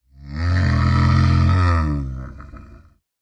assets / minecraft / sounds / mob / camel / stand5.ogg